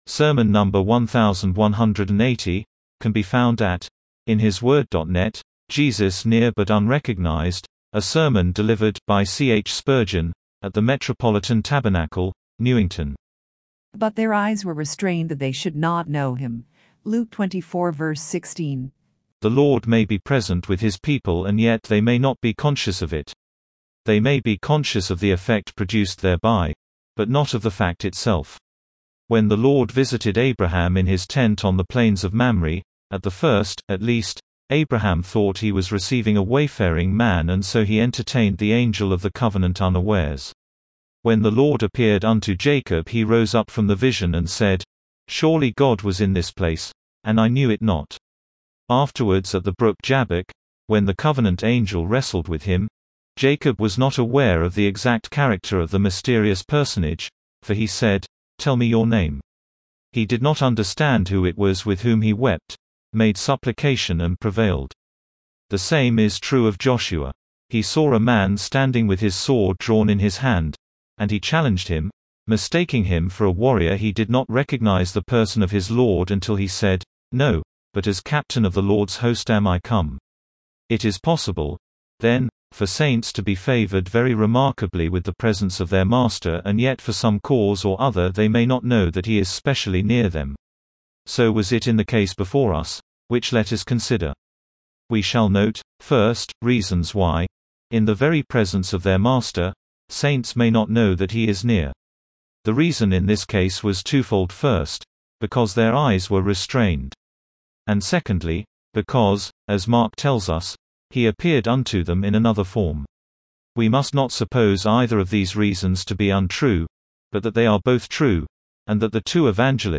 Sermon #1,180, JESUS NEAR BUT UNRECOGNIZED A SERMON DELIVERED, BY C. H. SPURGEON AT THE METROPOLITAN TABERNACLE, NEWINGTON.